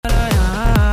מחפש את הסאונד הזה שבהקלטה שדומה מאוד לסנרים, למישהו יש?